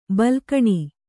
♪ balkaṇi